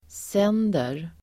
Uttal: [s'en:der]